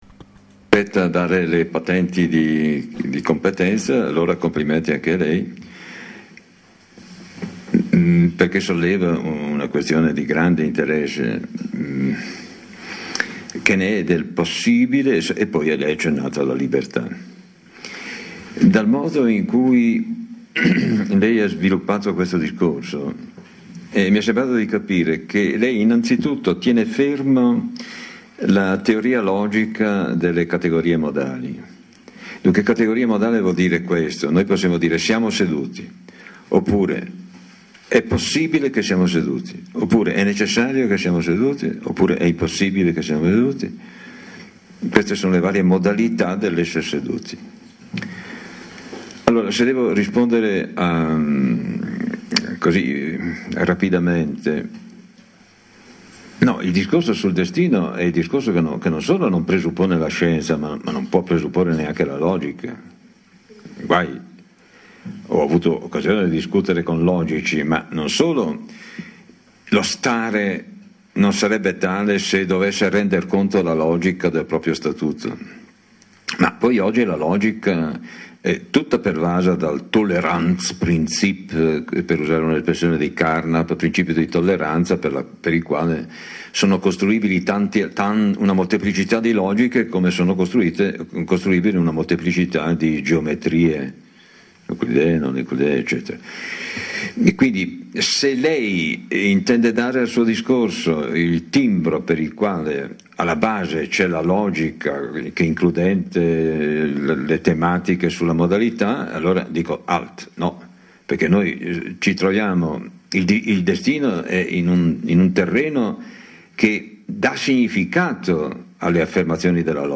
da: Emanuele Severino, La STORIA, l’ALDILA’, il DESTINO, 13 Video Lezioni, 12-19 Luglio 2008, Soprabolzano (BZ).